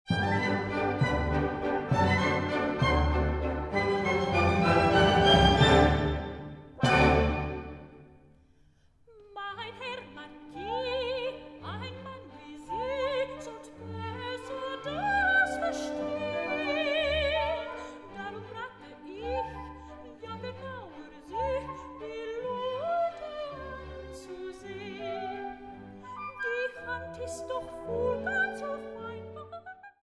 Félix du meilleur album classique, ADISQ 1998.